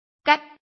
臺灣客語拼音學習網-客語聽讀拼-南四縣腔-入聲韻